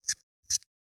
503,桂むき,大根の桂むきの音切る,包丁,厨房,台所,野菜切る,咀嚼音,ナイフ,調理音,
効果音厨房/台所/レストラン/kitchen食材